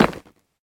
Minecraft Version Minecraft Version latest Latest Release | Latest Snapshot latest / assets / minecraft / sounds / block / nether_bricks / step1.ogg Compare With Compare With Latest Release | Latest Snapshot
step1.ogg